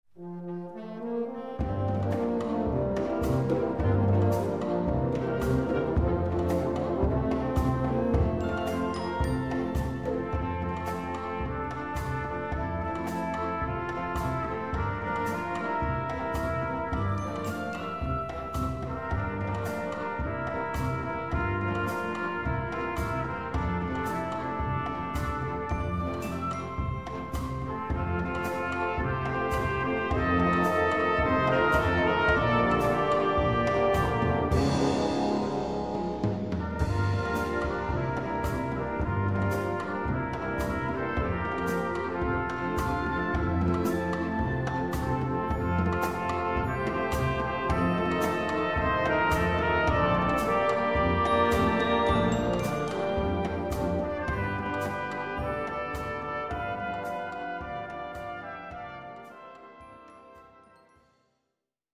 Solo für 2 Trompeten
Besetzung: Blasorchester